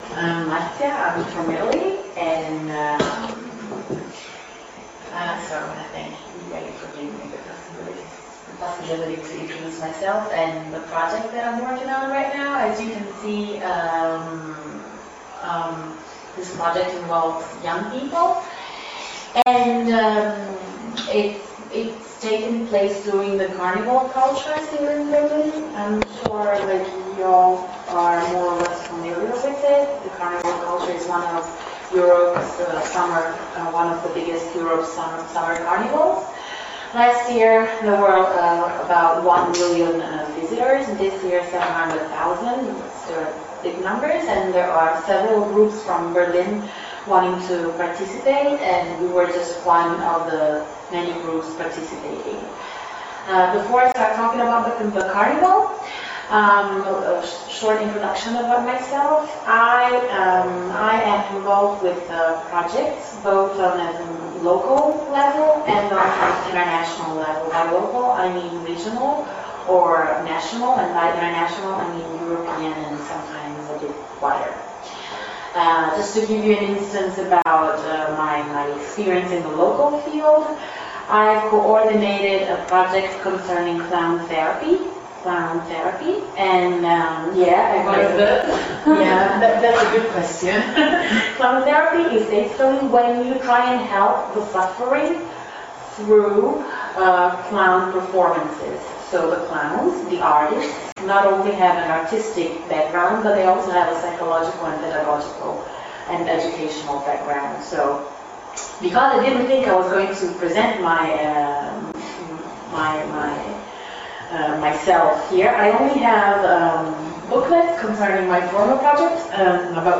Kunst Apotheke Salon Session 1
Audio Extract of 27 Pinguine presentation http